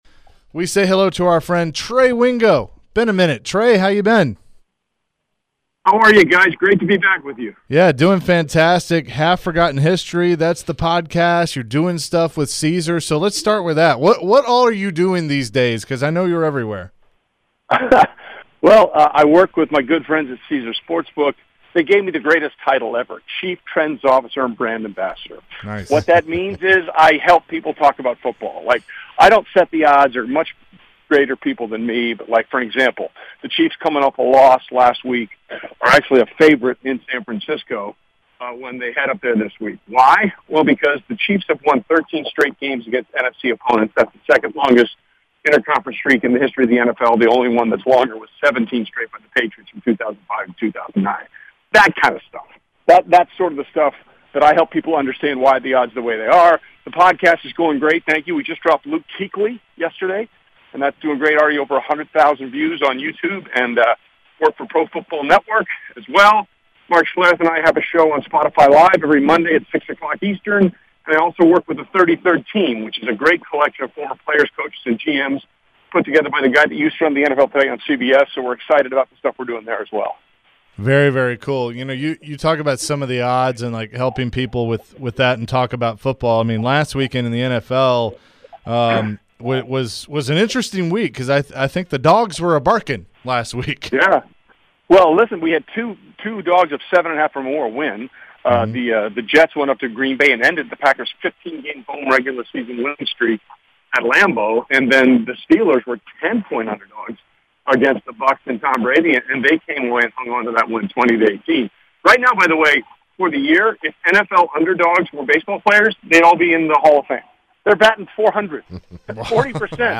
Trey Wingo interview (10-21-22)